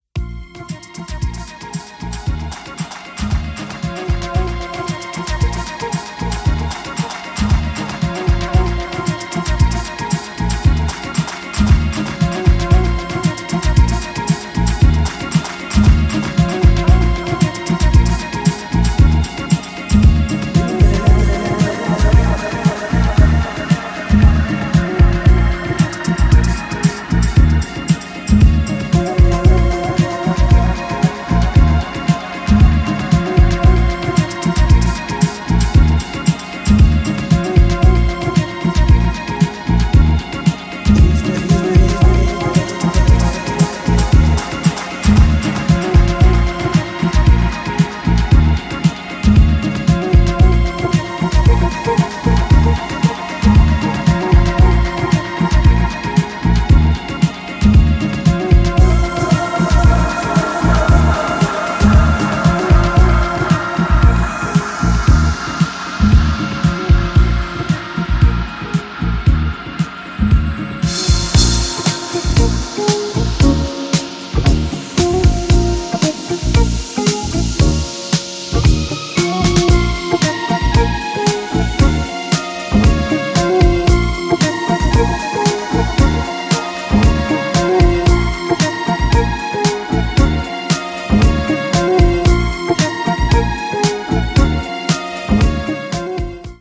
特にA面、他の人はまずやらないだろう執拗さが光ってますね。